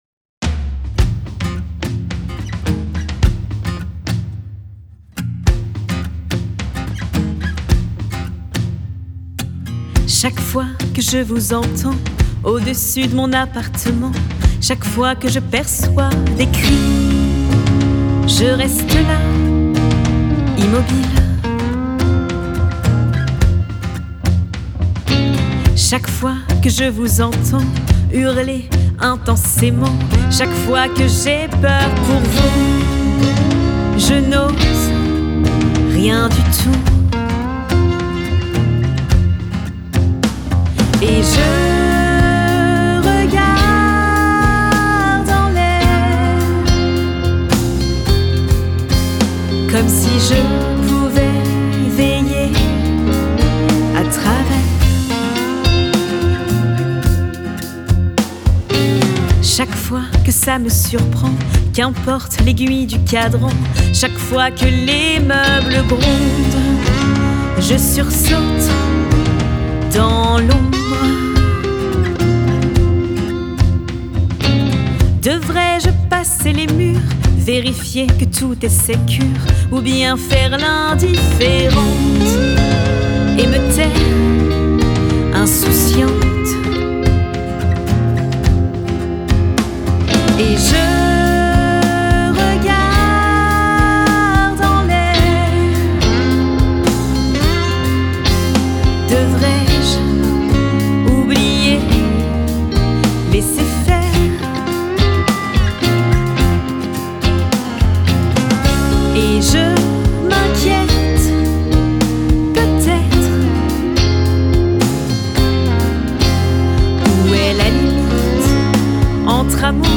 Elle écrit, compose et chante.